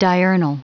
Prononciation du mot diurnal en anglais (fichier audio)
Prononciation du mot : diurnal